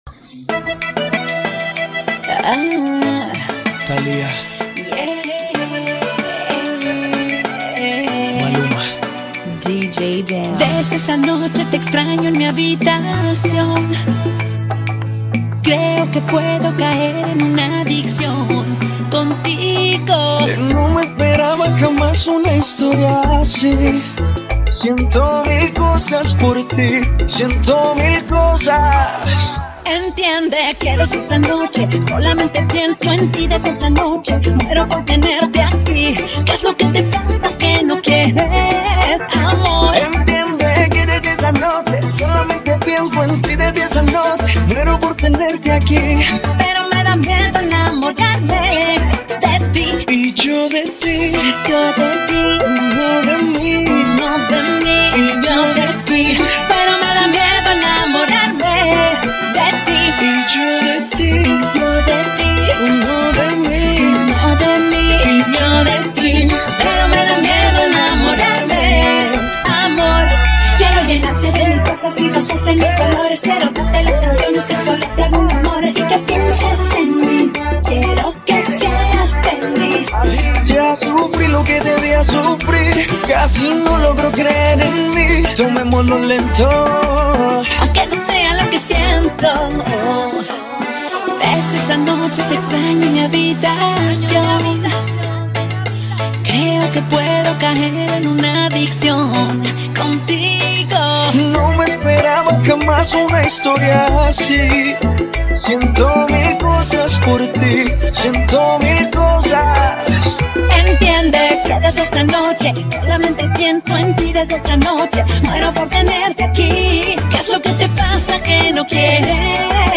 95 BPM
Genre: Salsa Remix